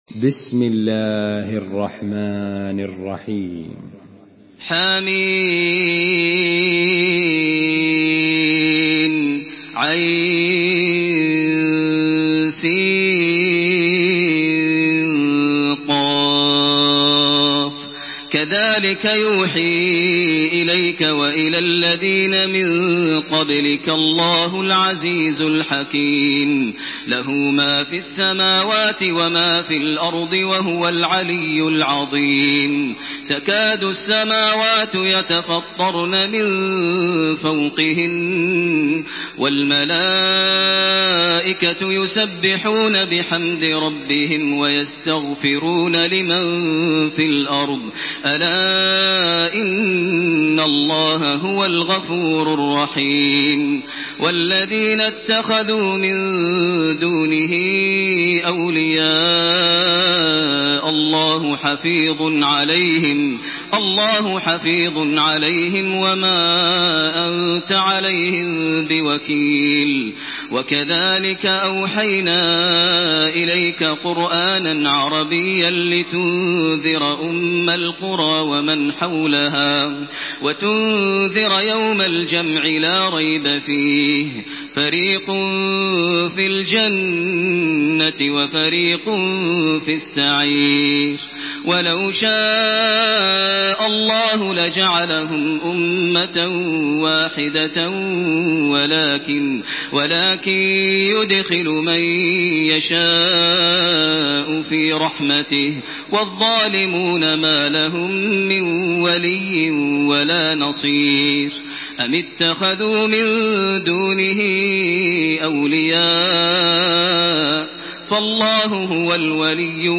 Surah আশ-শূরা MP3 by Makkah Taraweeh 1428 in Hafs An Asim narration.
Murattal Hafs An Asim